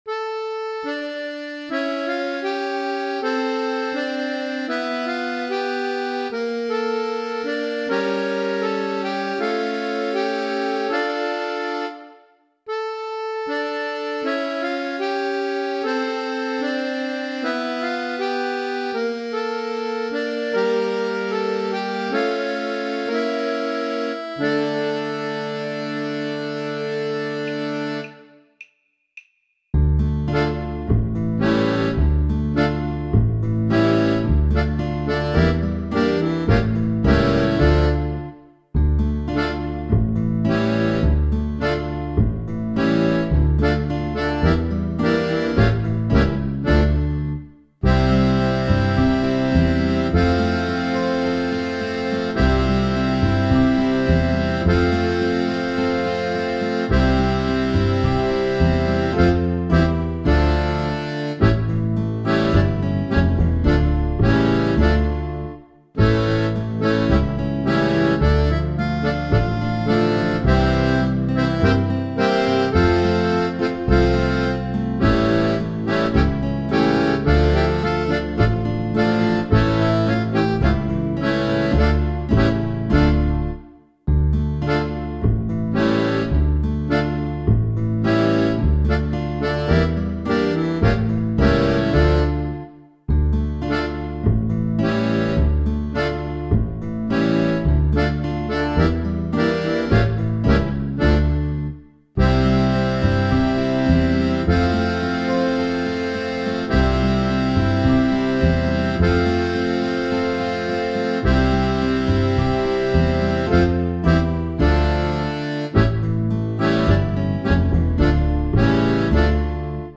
Runterladen (Mit rechter Maustaste anklicken, Menübefehl auswählen)   Dos Kelbl (Donaj Donaj) (Playback)
Dos_Kelbl_Donaj_Donaj__5_Playback.mp3